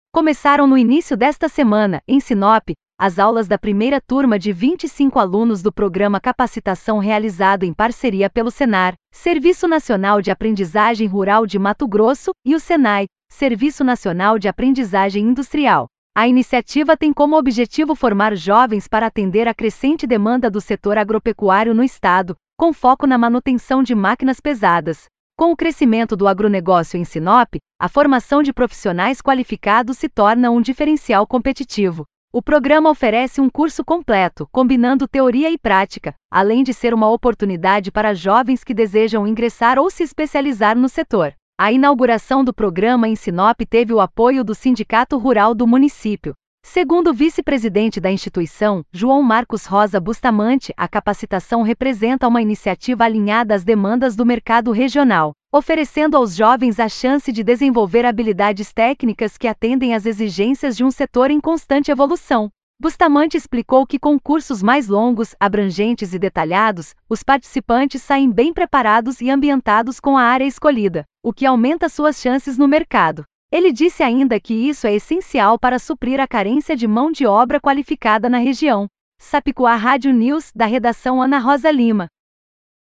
Voz: